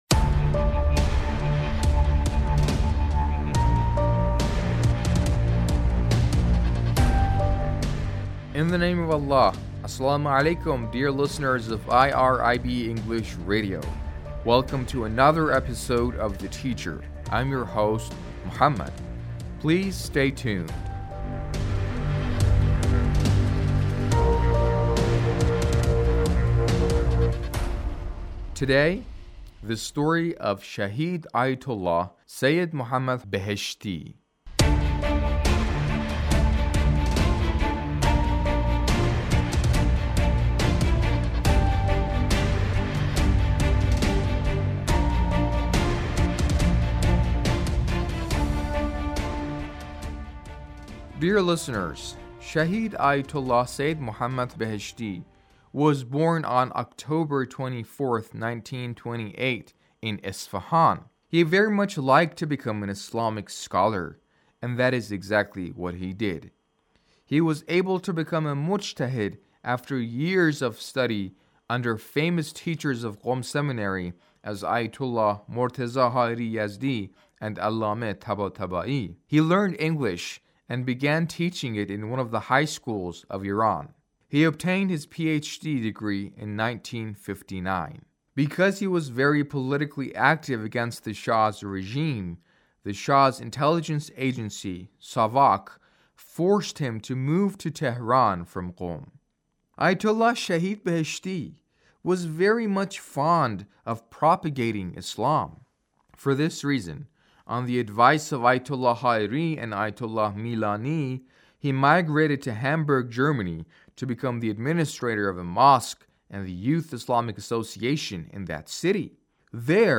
A radio documentary on the life of Shahid Ayatullah Dr. Beheshti